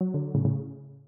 call_declined-1jWRbd4p.mp3